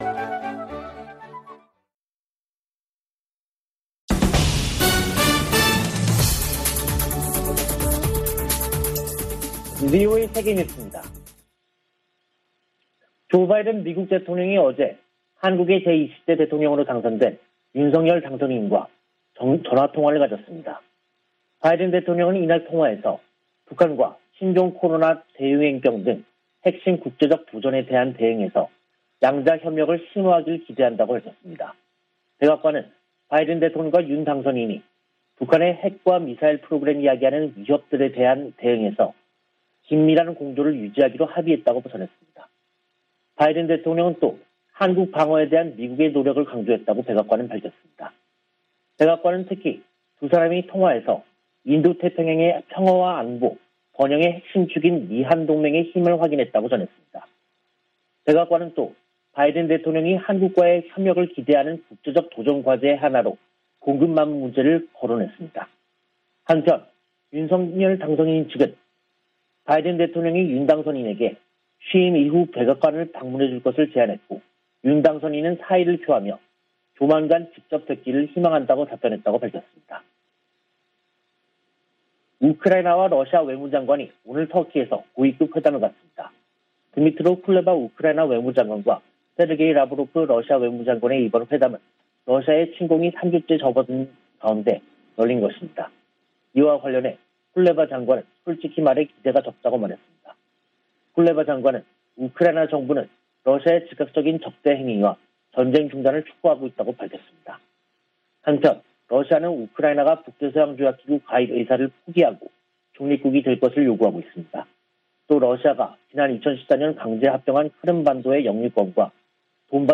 VOA 한국어 간판 뉴스 프로그램 '뉴스 투데이', 2022년 3월 10일 2부 방송입니다. 한국 대통령 선거에서 윤석열 후보가 승리했습니다.